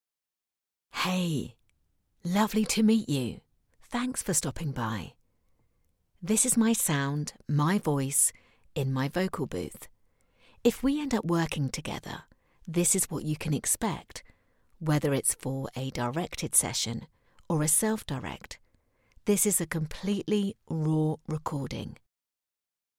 Female
English (British)
My voice has been described as friendly, rich, warm, relatable, and approachable.
Natural Talking Voice
Studio Quality Sample
Words that describe my voice are Relatable, Rich, Approachable.